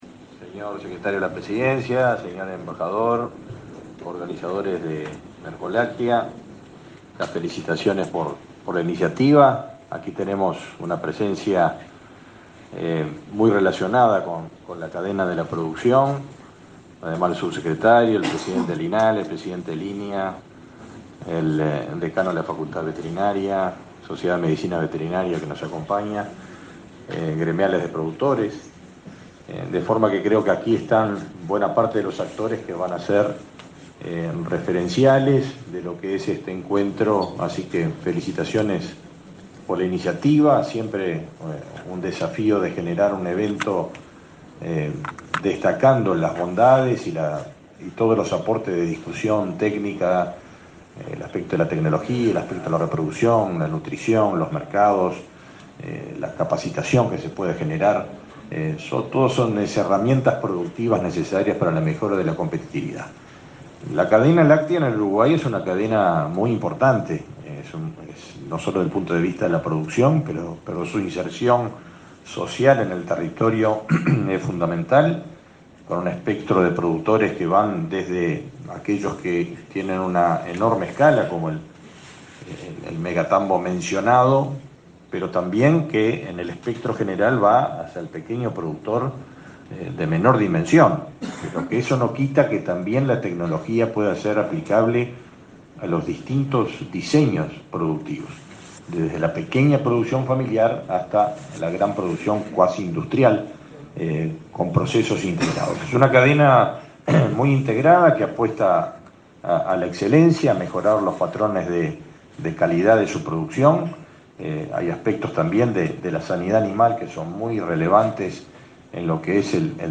Palabras del ministro del MGAP, Fernando Mattos
Mattos oratoria.mp3